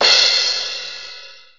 • Big Crash Cymbal Drum Sample C Key 02.wav
Royality free drum crash tuned to the C note.
big-crash-cymbal-drum-sample-c-key-02-bhK.wav